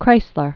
(krīslər), Fritz 1875-1962.